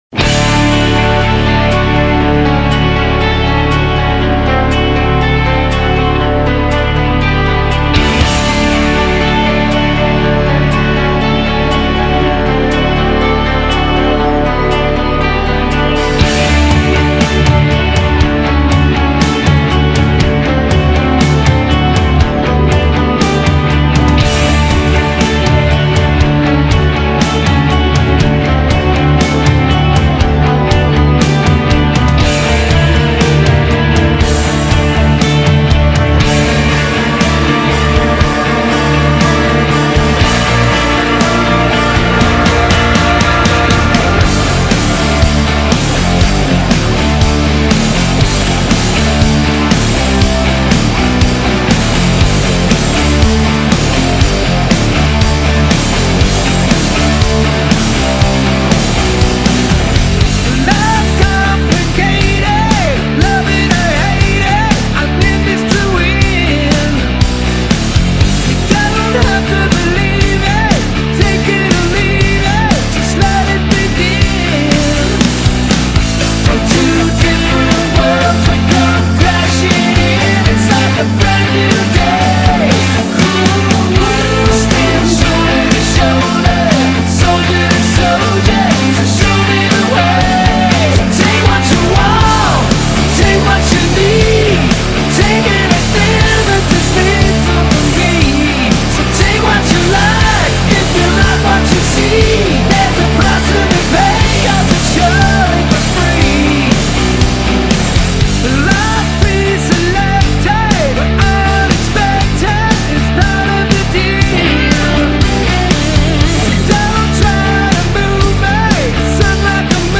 Hard Rock, Glam Rock